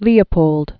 (lēə-pōld) 1640-1705.